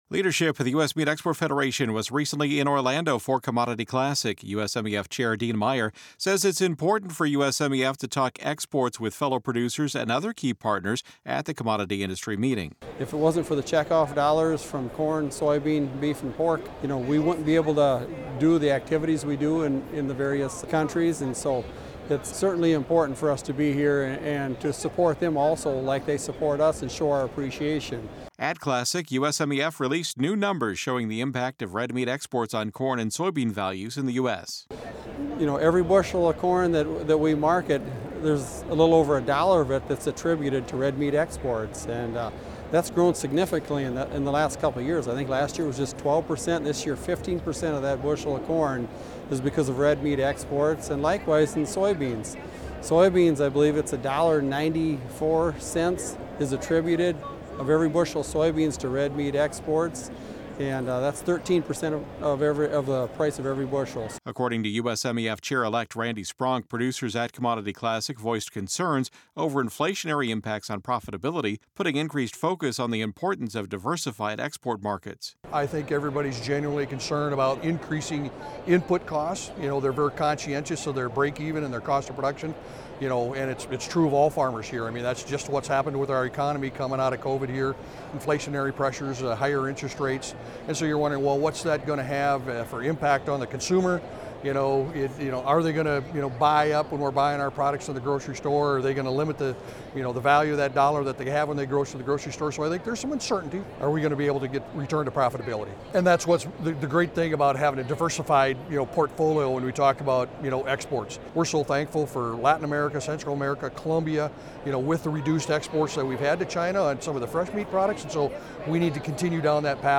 USMEF leaders were at Commodity Classic in Orlando last week discussing the impact red meat exports have on corn and soybean prices.